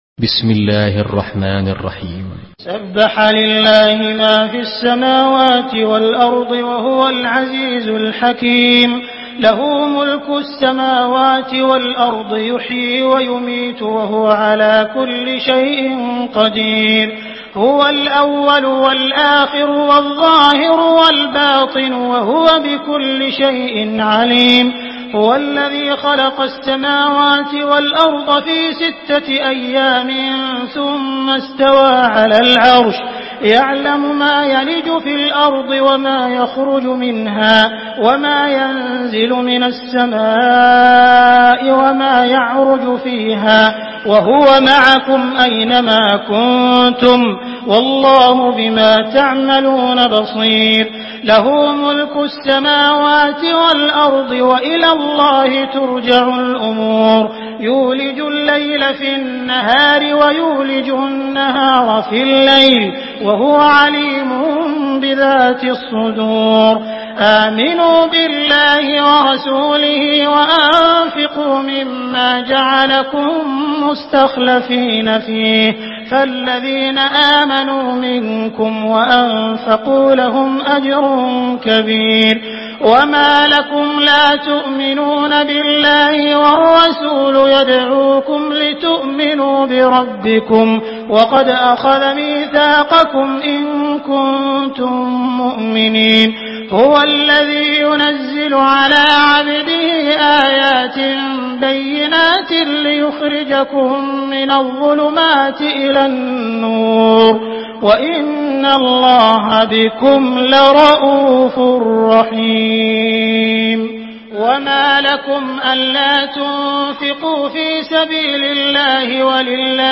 Surah আল-হাদীদ MP3 by Abdul Rahman Al Sudais in Hafs An Asim narration.
Murattal Hafs An Asim